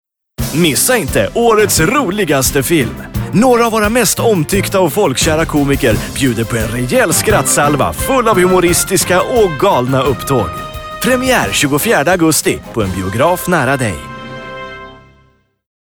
SV JL COM 01 Commercials Male Swedish